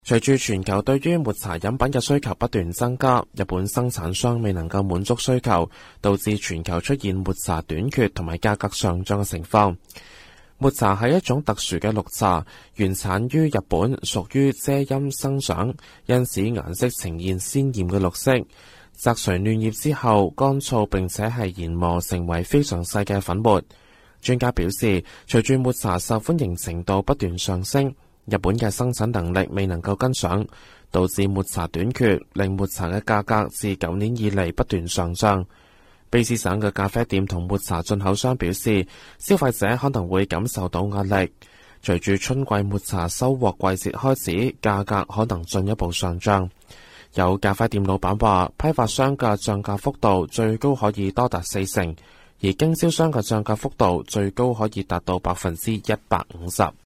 news_clip_23247.mp3